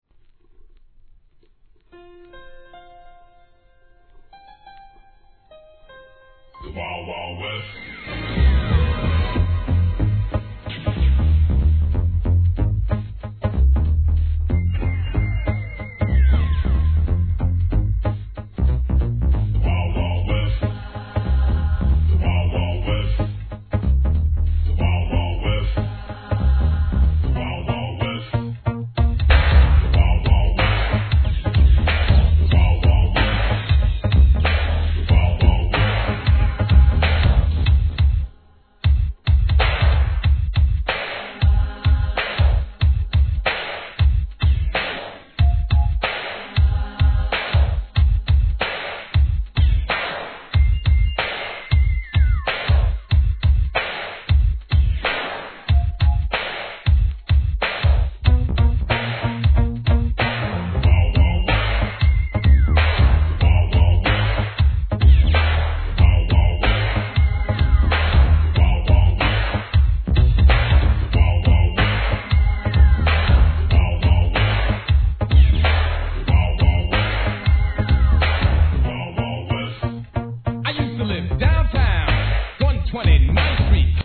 HIP HOP/R&B
1988年、銃声のサンプリングに夕焼けガンマン風サウンドのCOWBOY作!!